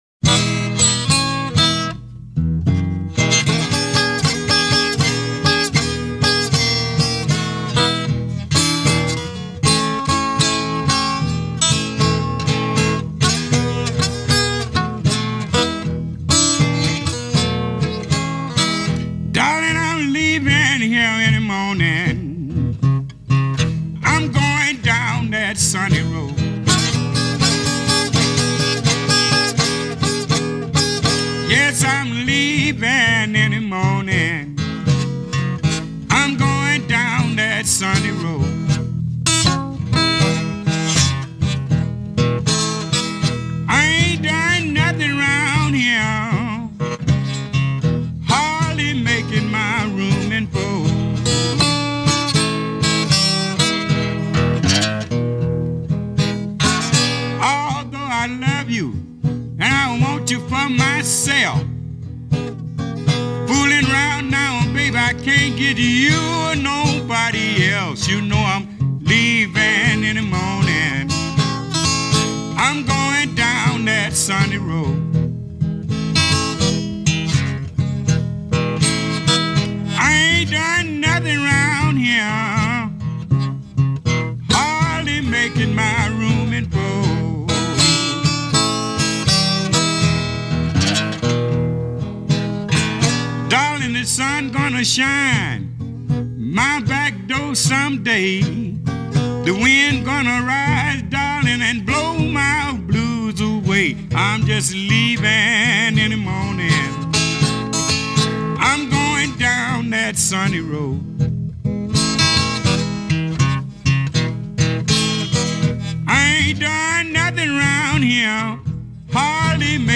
blues
A Delta Blues singer, going back to the 1930s